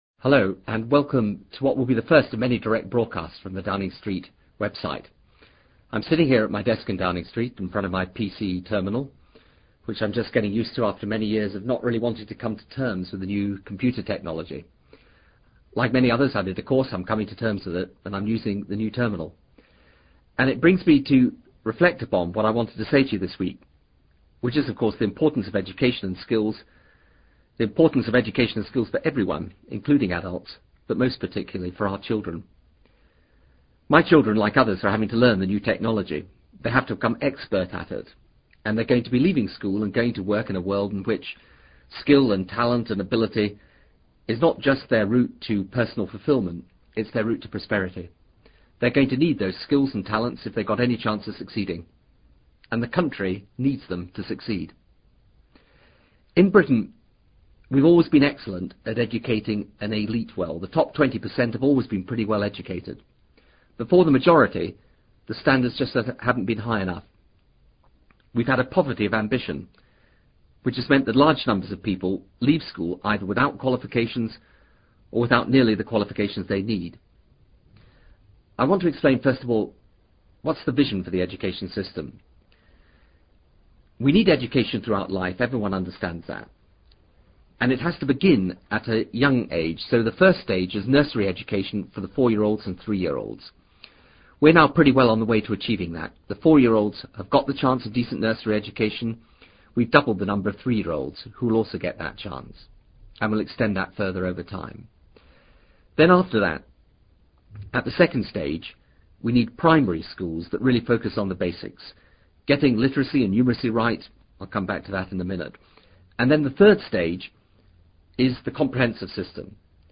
TRANSCRIPT OF THE PRIME MINSTER'S FIRST AUDIO BROADCAST ON THE NUMBER 10 WEBSITE. 11 FEBRUARY 2000